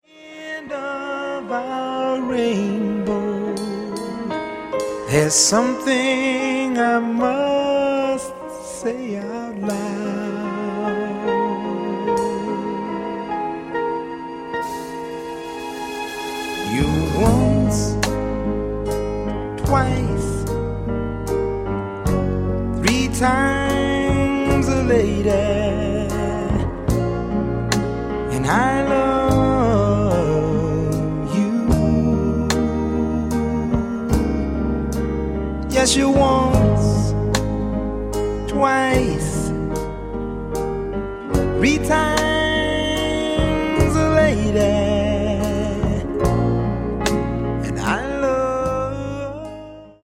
VOLUME :: 69 :: - LOVE BALLADS -